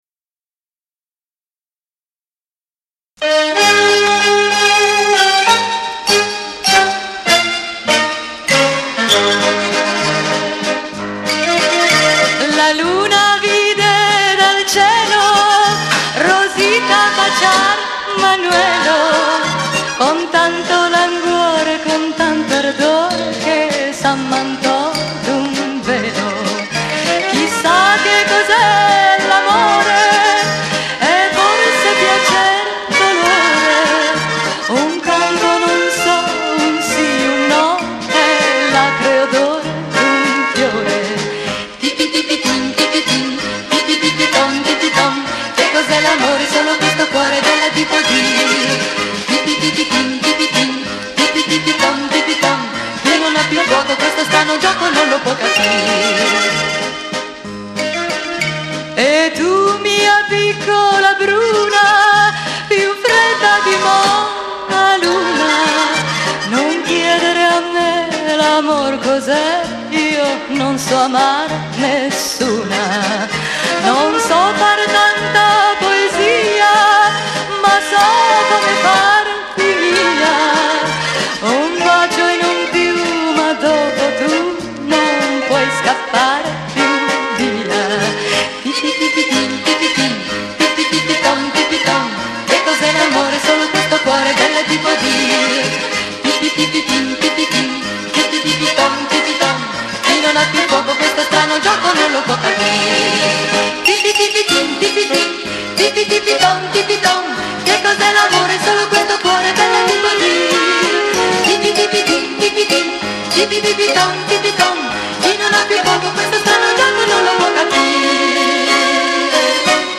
VALZER